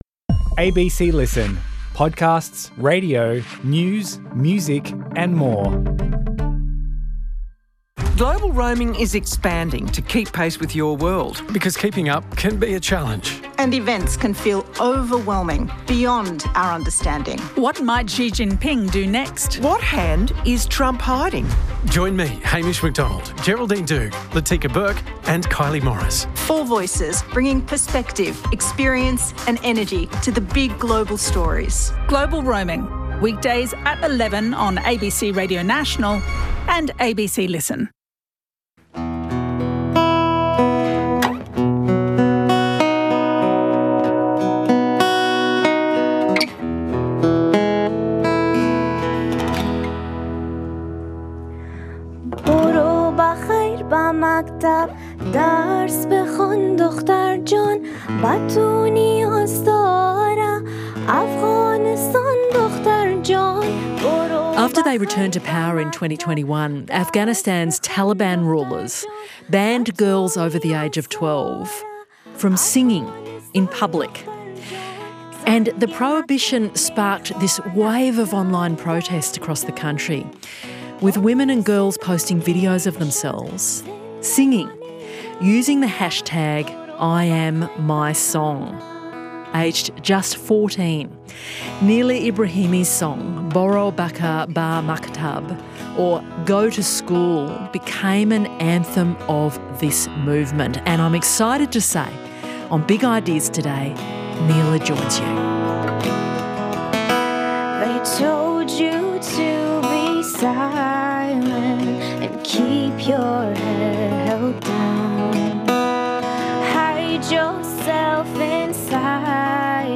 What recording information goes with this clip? Thanks to the Australian Museum for hosting and producing this event.